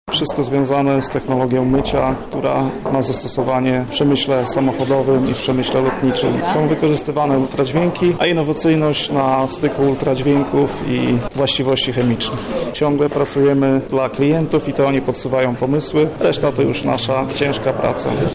Gala Przedsiębiorczości